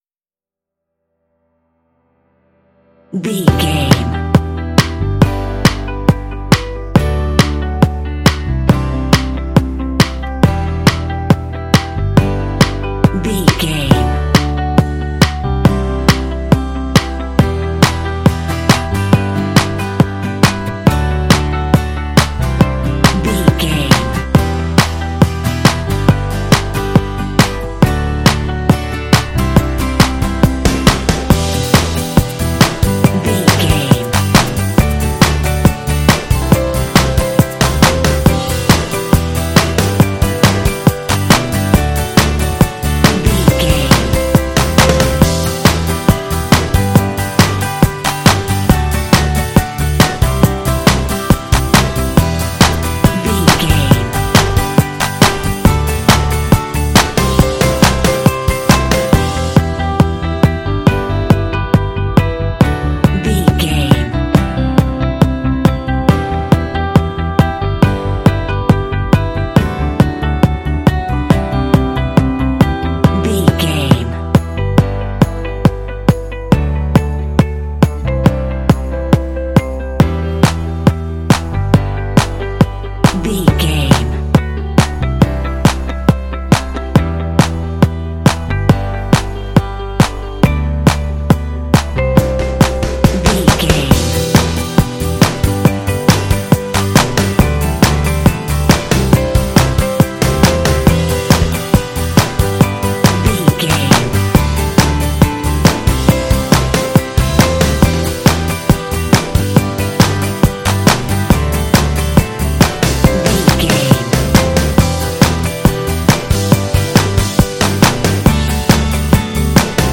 Ionian/Major
D
positive
bright
optimistic
happy
inspirational
percussion
bass guitar
brass
horns
alternative rock